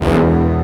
50 Cent Brass Hit.wav